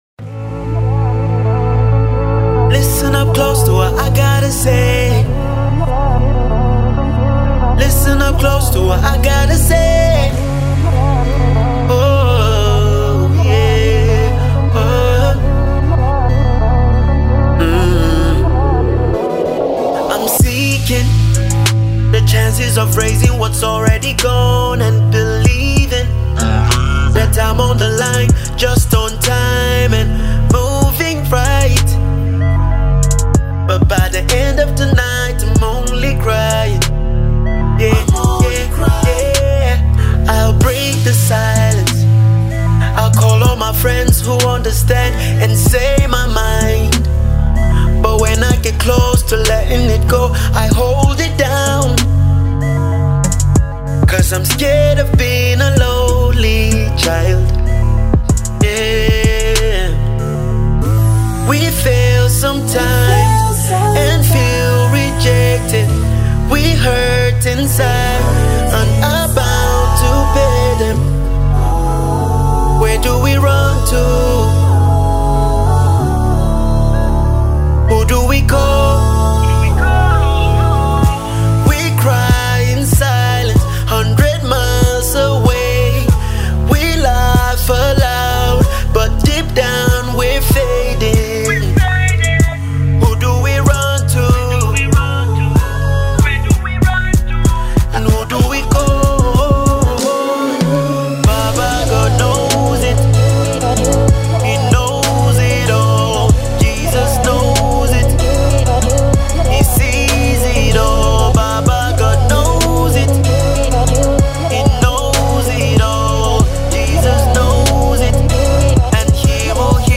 a calm R&B song